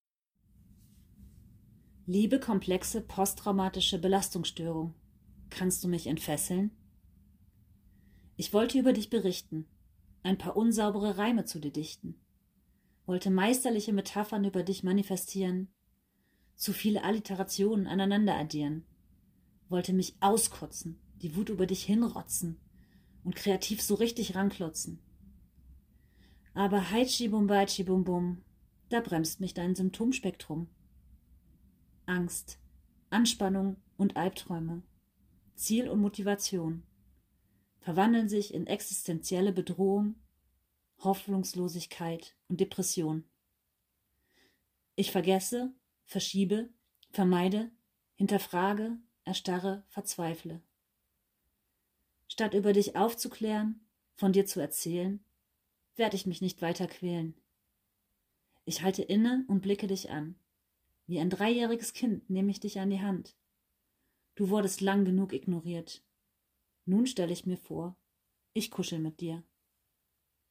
🫧 Ein Gedicht übers Entfesseln 🫧